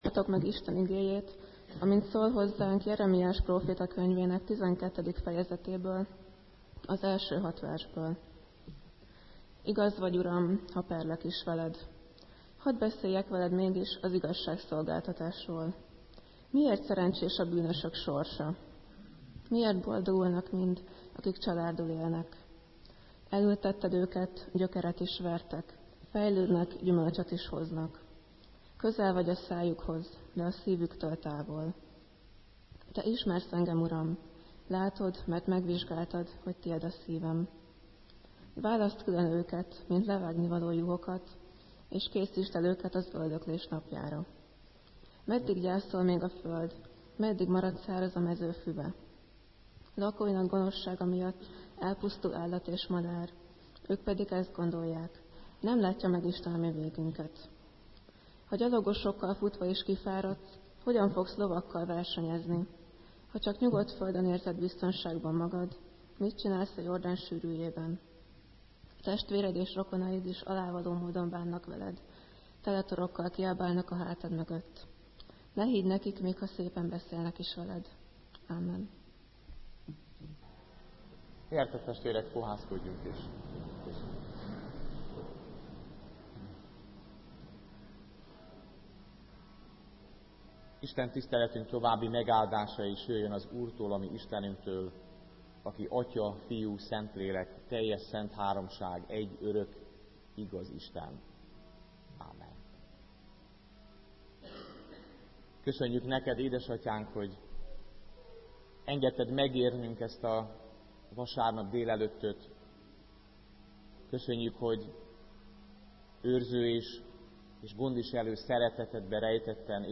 Igehirdetések Biztos horgonyok